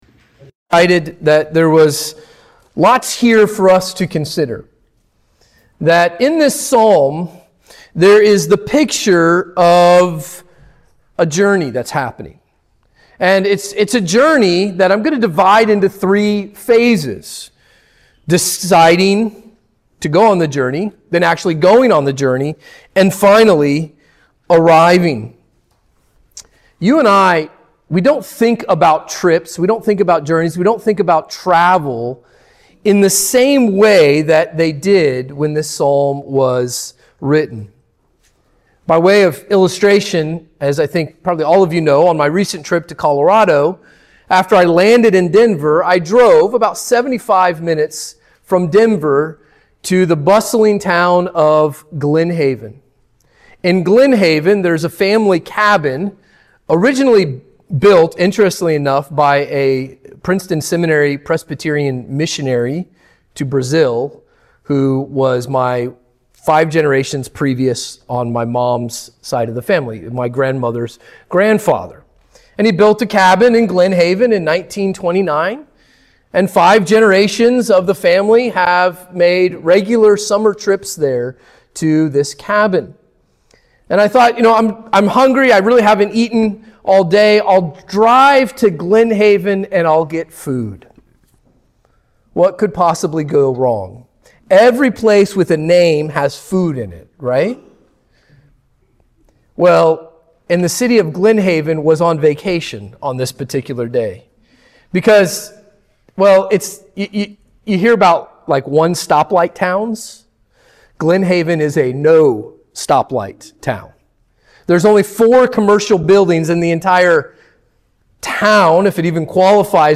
2025 Book of Psalms Psalm Sunday Morning Worship Introduction